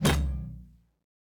parry_2.ogg